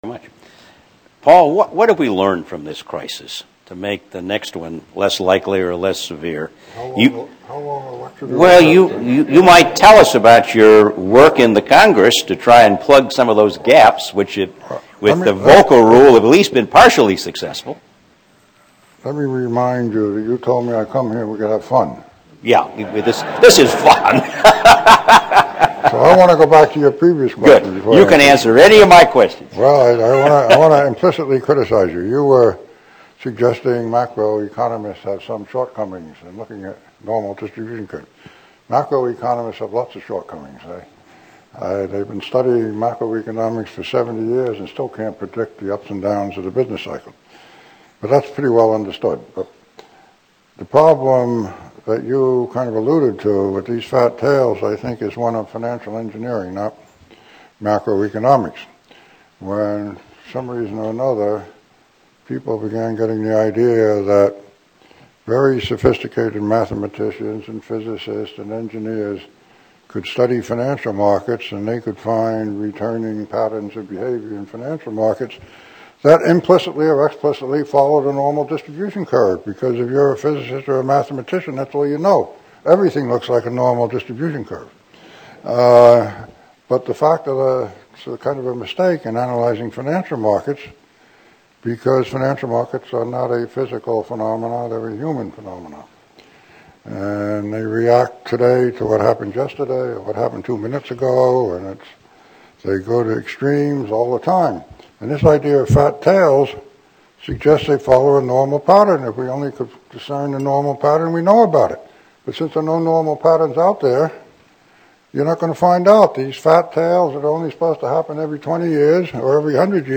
Recently, at the University of Virginia, Miller Center, there was a forum of Central Bankers.  Paul Volcker was one of the participants.  At one point when he was asked to respond, in just a few minutes, he summed up the entire problem when he talked about the financial engineers shifting rents.